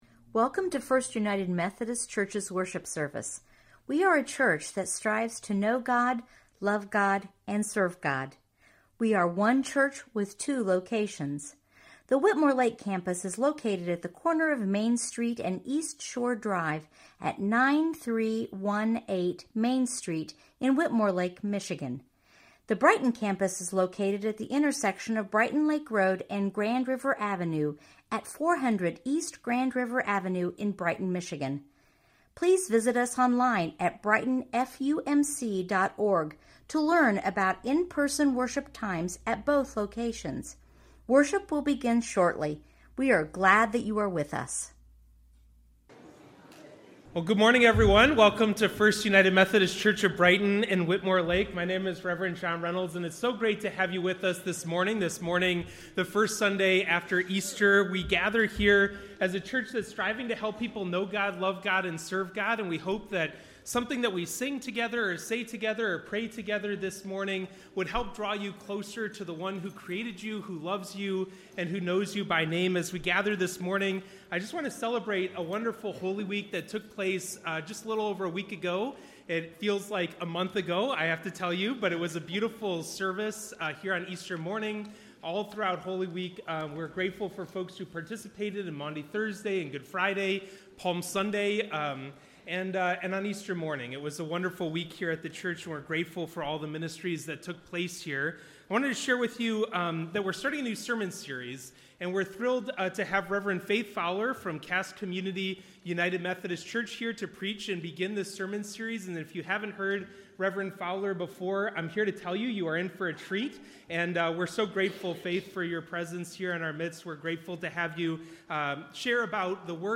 preaches on Genesis 1:3-5 and Psalm 19:1-6